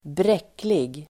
Uttal: [²br'ek:lig]